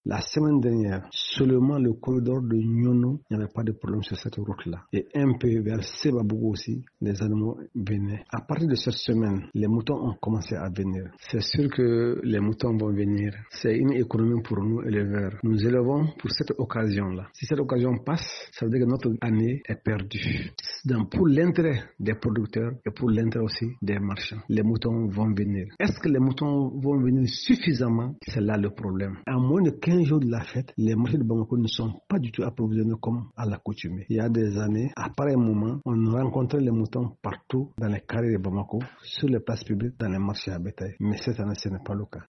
Nous sommes au marché à bétails de Ngouma.